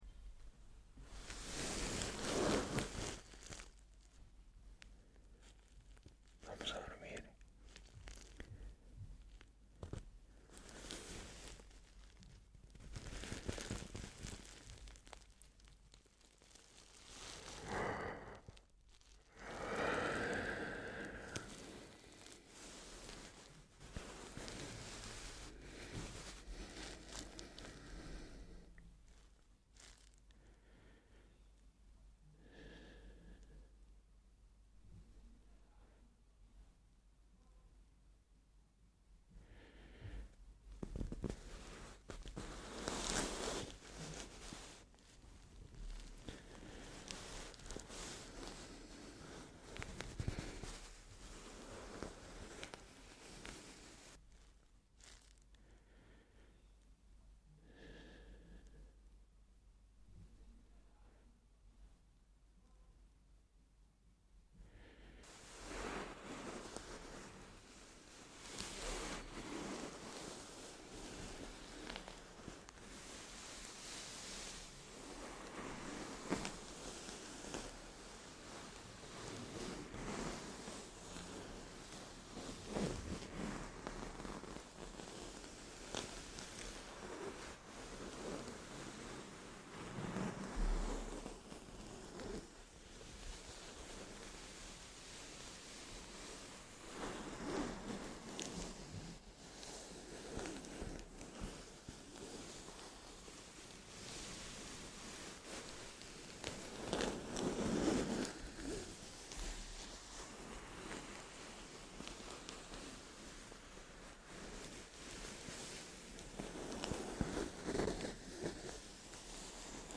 ASMR para dormir - Sábanas y almohadas